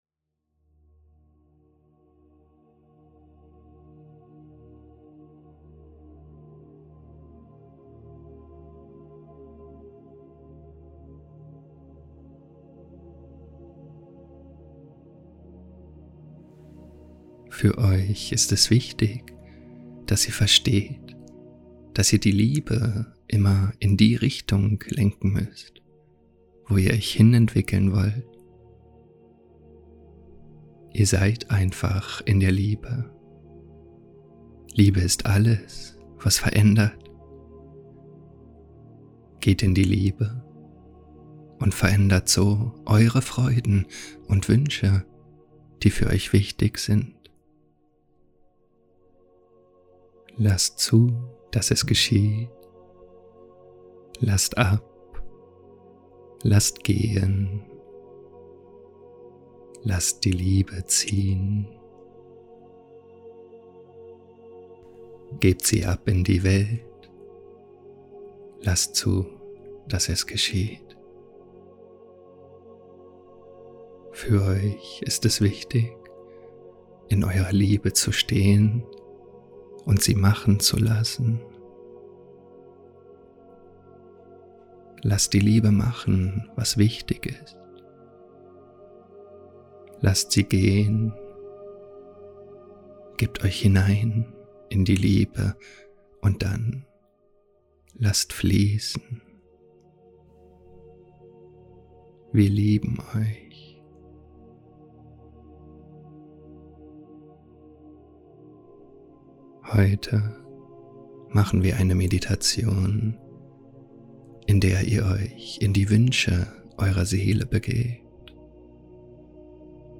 In dieser kraftvollen Meditation wirst du mit deiner wahren Essenz verbunden und beginnst, deine Träume in die Realität zu bringen. Was dich erwartet: Eine tiefgehende Verbindung zu deiner Seele und ihren wahren Wünschen.